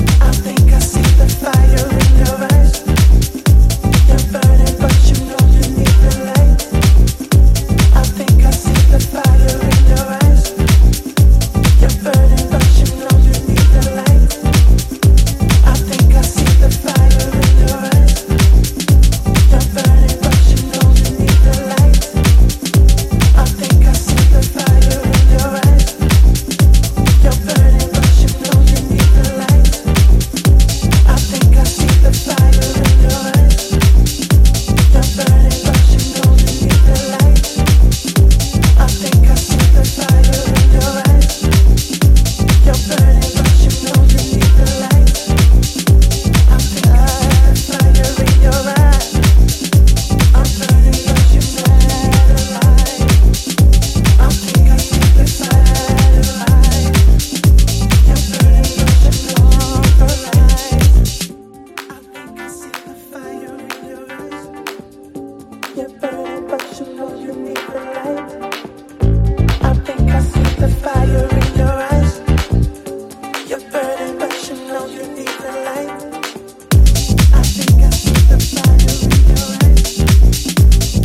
bumping RnB-tinged, hazy house bomb